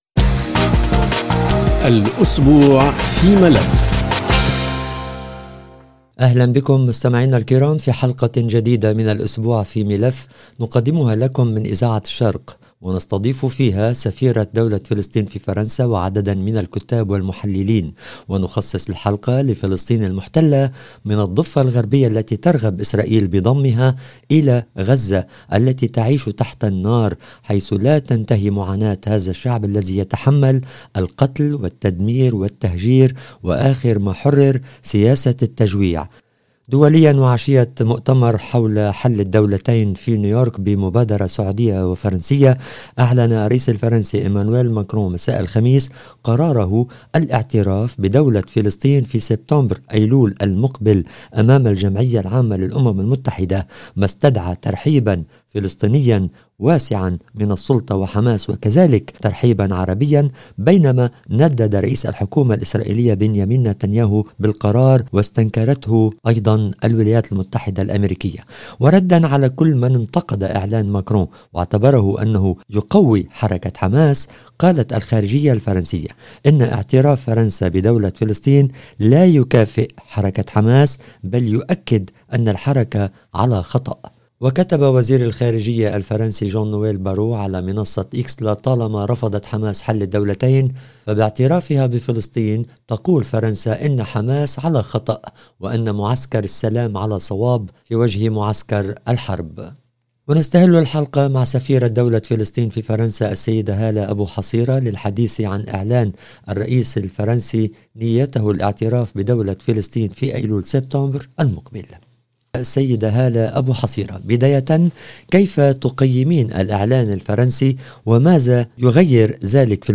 الاسبوع في ملف خاص بالشان الفلسطيني من الضفة الغربية المحتلة الى غزة المحاصرة واعلان الرئيس الفرنسي نيته الاعتراف بدولة فلسطين في ايلول المقبل. ونستضيف في الحلقة سفيرة دولة فلسطين في فرنسا وعدداً من المختصين والمحللين والباحثين والمسؤلين الأمميين.